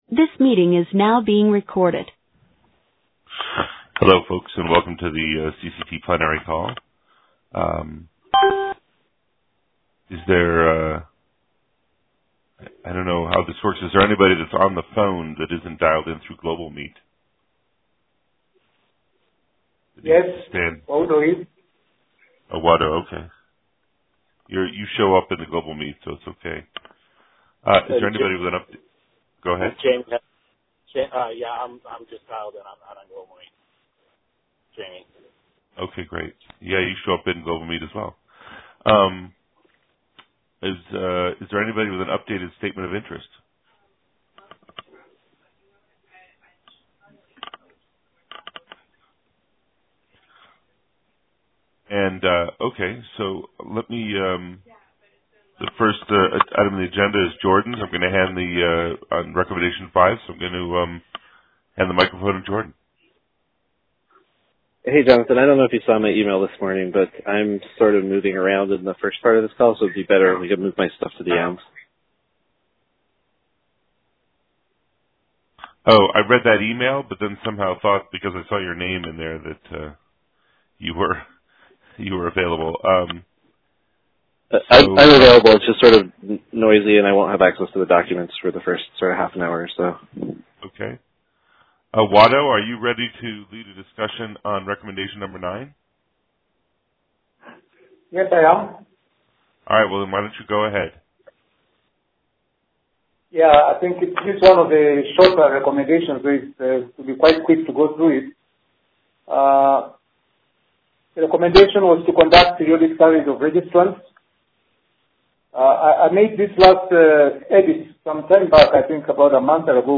cct-review-plenary-21mar18-en.mp3